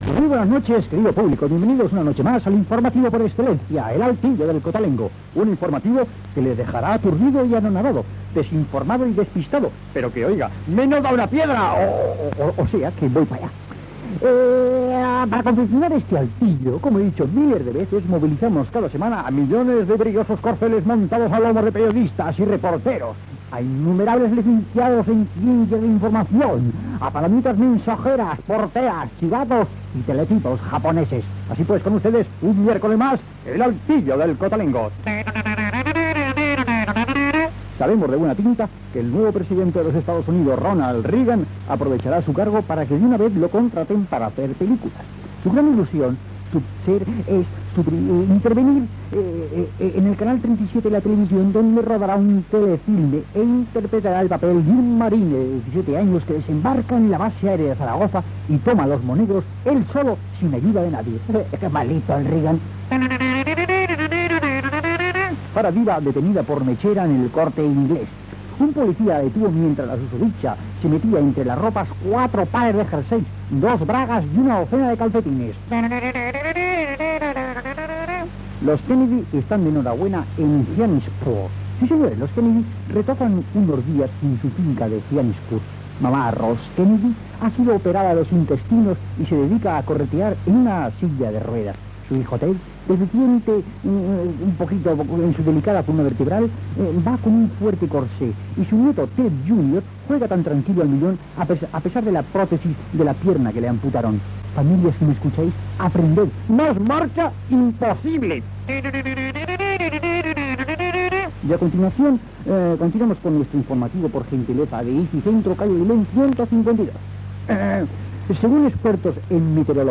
El altillo del cotolengo, informatiu humorístic
Entreteniment
FM